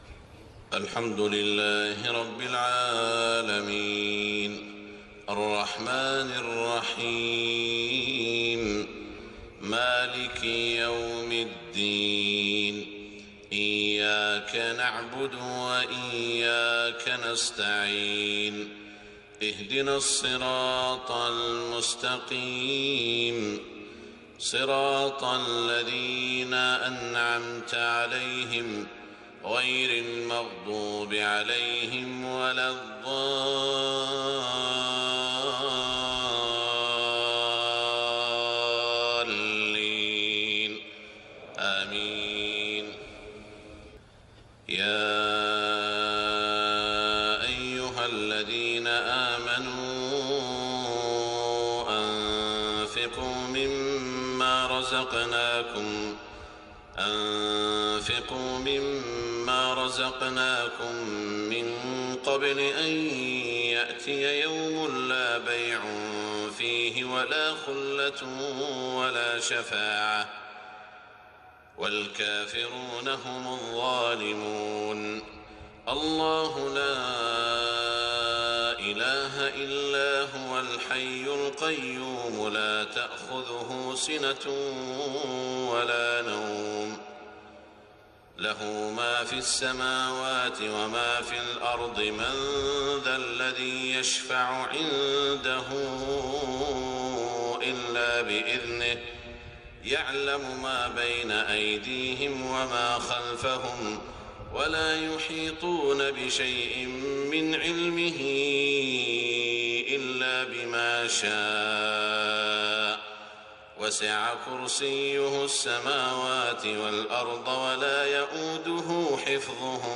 صلاة الفجر 22 محرم 1429هـ من سورة البقرة > 1429 🕋 > الفروض - تلاوات الحرمين